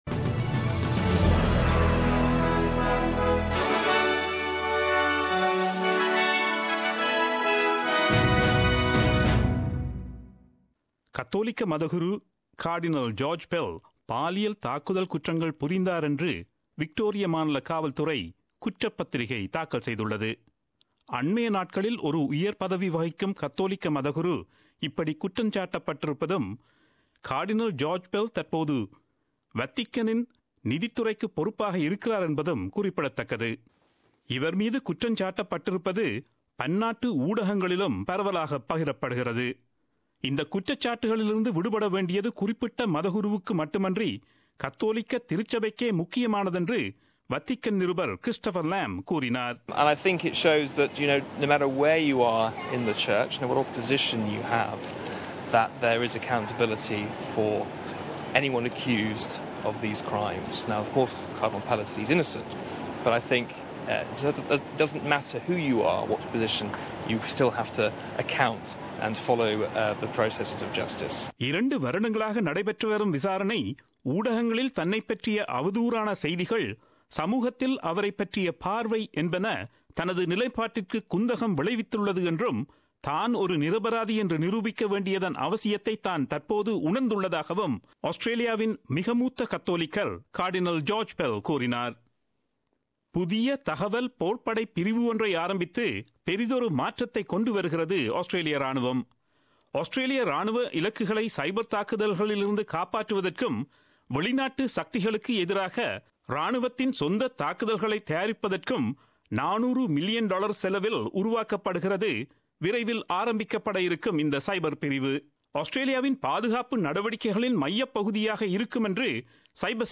Australian news bulletin aired on Friday 30 June 2017 at 8pm.